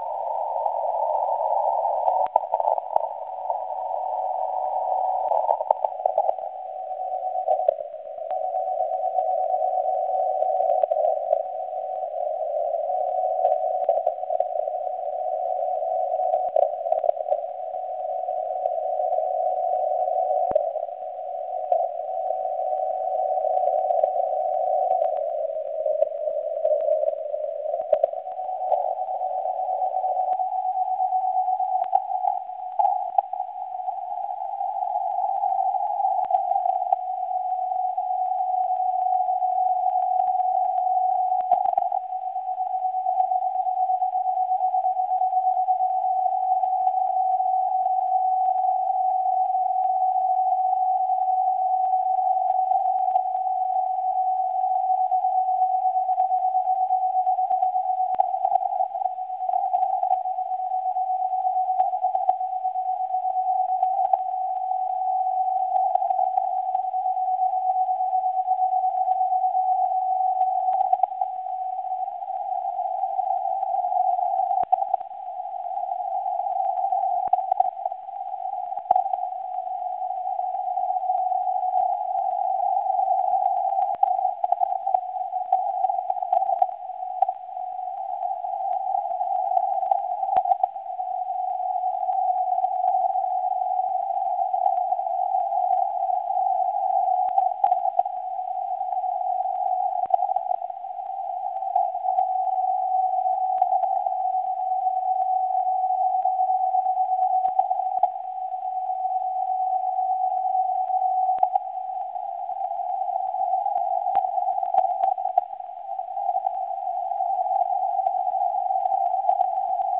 Due the lack of public MF grabbers on the net, last night I’ve tried to receive my signal in qrss10 with remote WebSDR system.
With great surprise receiving myself on Twente WebSDR my signal was audible and even a bit stronger than IQ2MI signal (used always to compare mine).
My signal is on higher frequency because WebSDR software uses reverse CW.